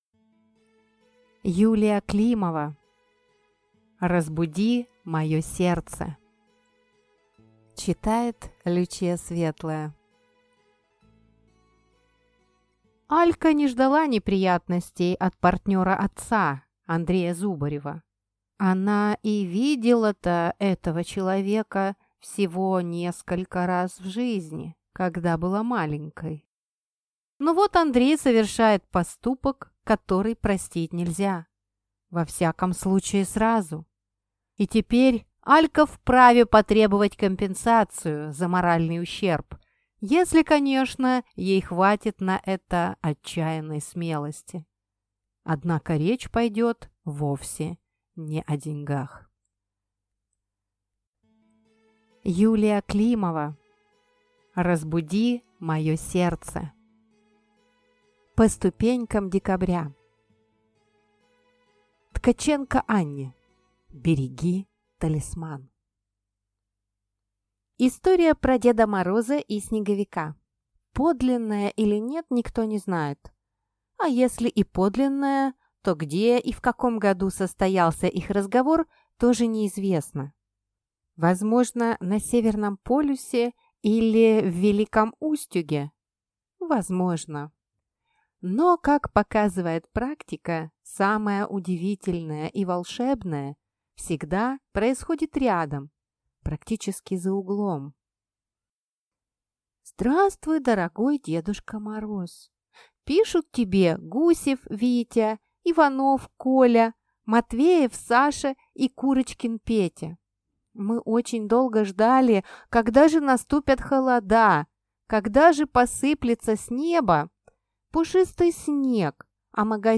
Аудиокнига Разбуди мое сердце | Библиотека аудиокниг